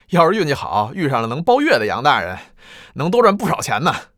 c01_9车夫_13.wav